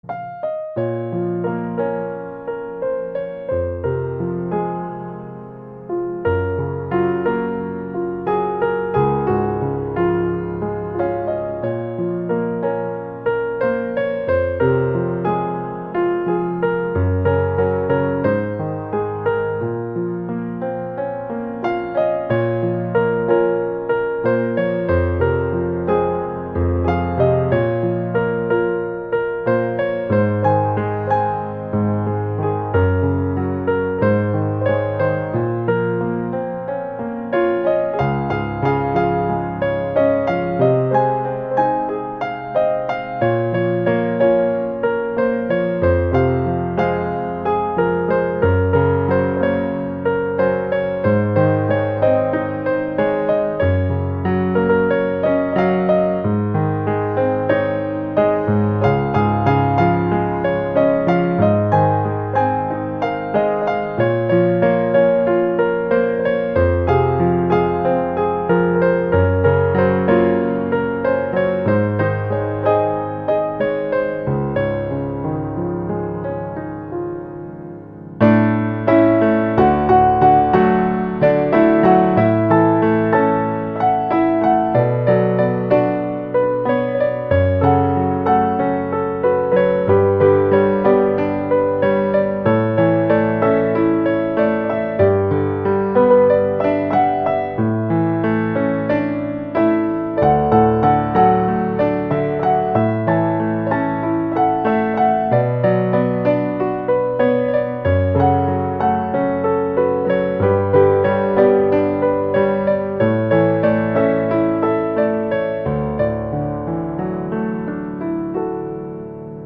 nhẹ nhàng, lãng mạn
bản nhạc không lời trong trẻo